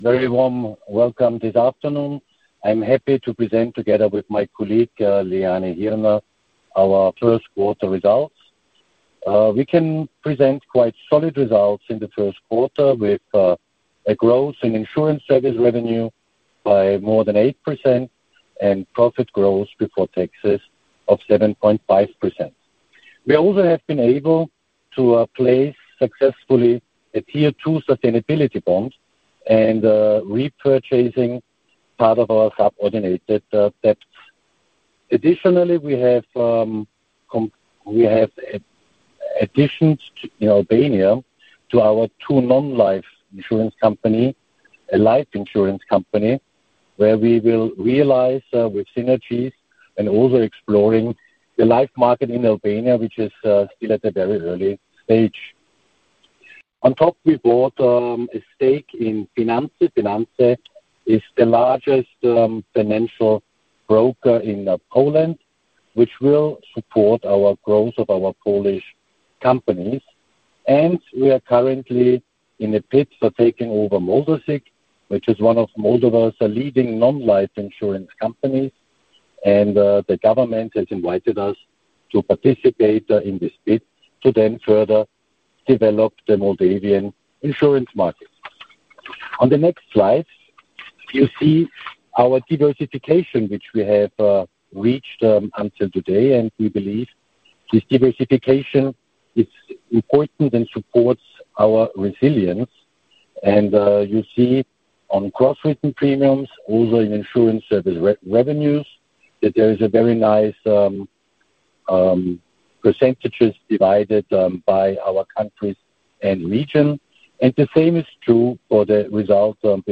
3m-2025-vig-teleconference.mp3